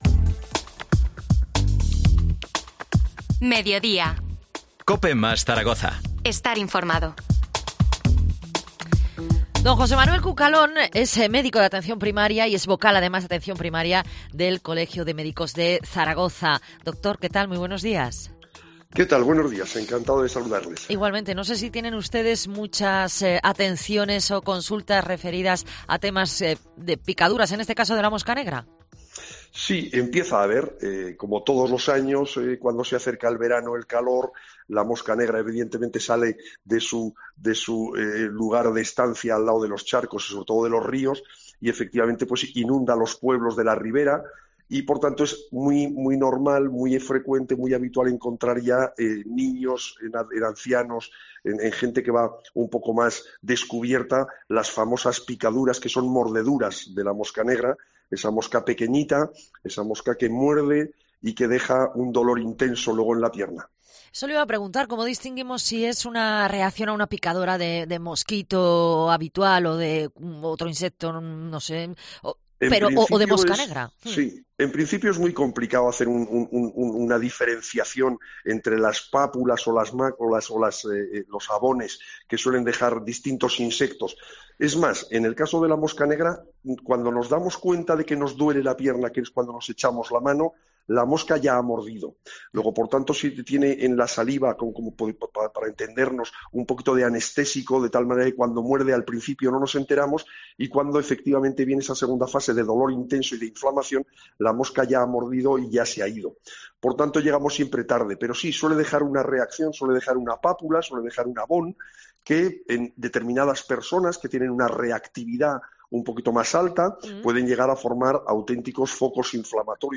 Entrevista al médico de Atención Primaria